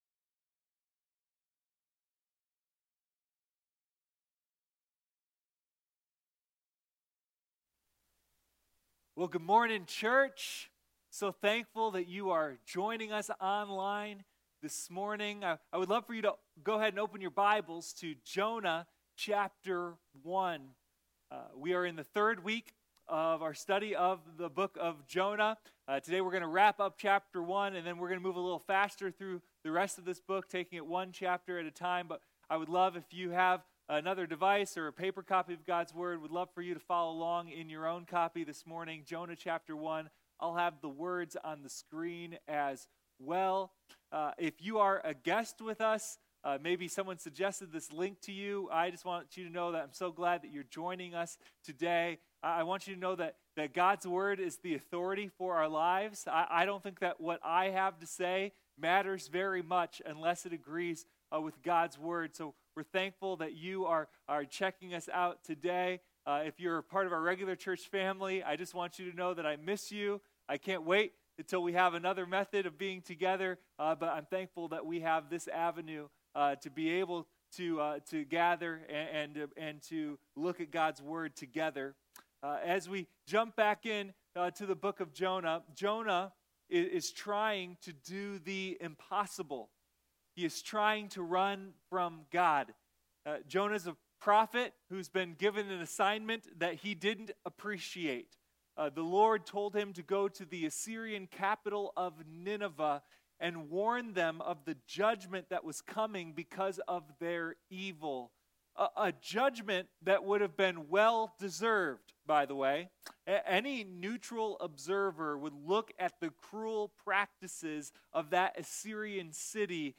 Sunday Morning Jonah: a deep dive into God's mercy